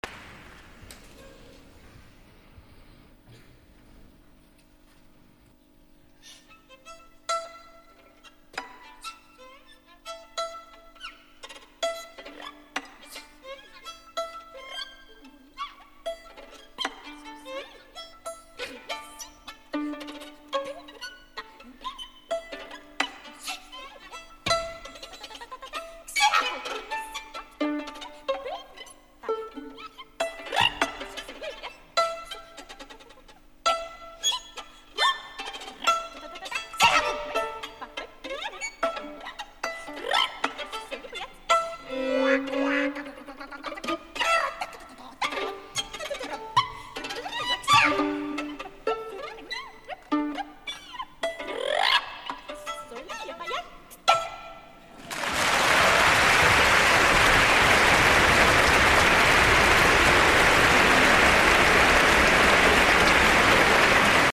L’Auditori de Barcelona diumenge 27 de febrer de 2011